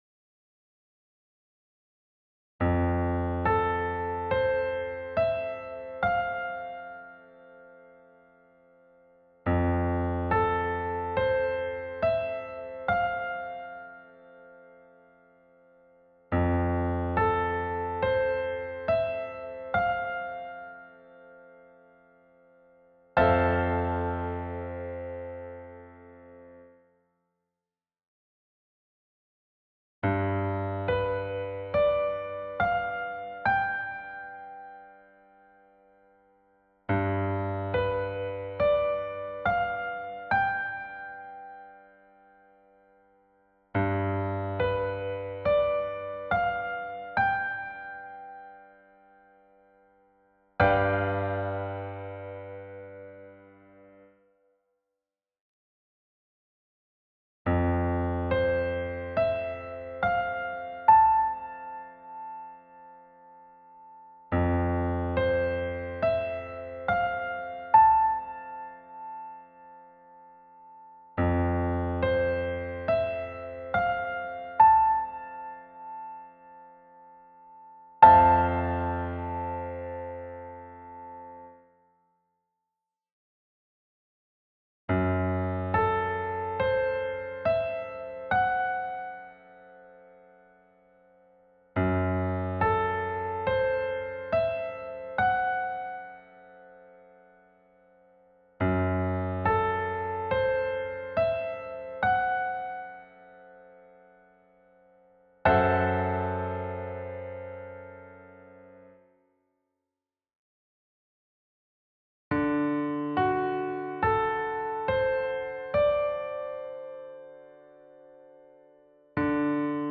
Riconoscimento e identificazione degli arpeggi(quadriadi in 1°-2°-3° rivolto)